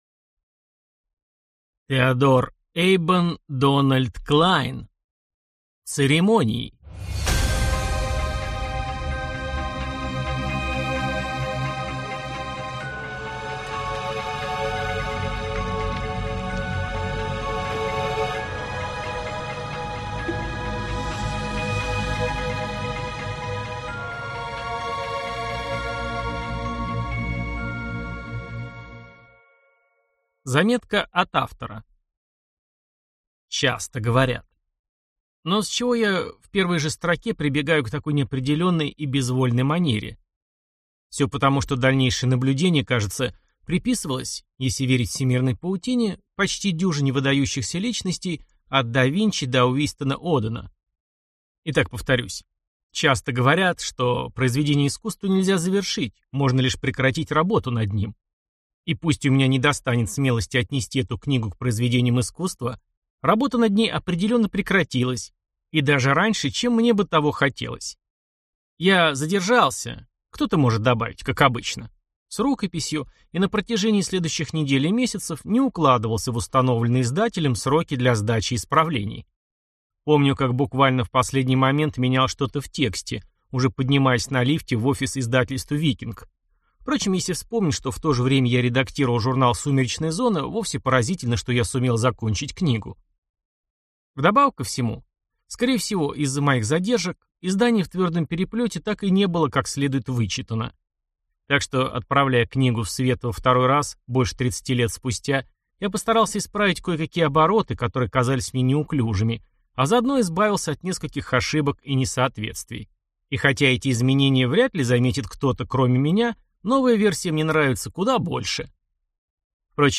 Аудиокнига Церемонии | Библиотека аудиокниг